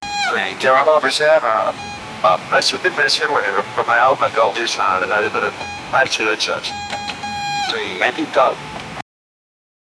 Jarvis voice
jarvis-voice-welcoming-mr-jwfrrr3u.wav